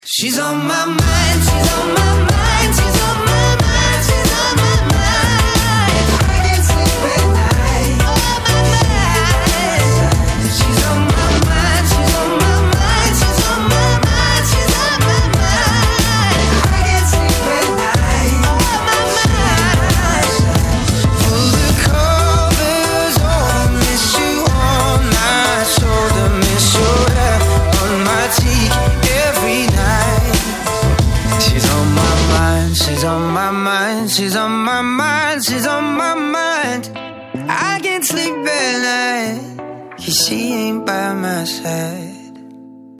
мужской вокал
dance
soul
танцевальные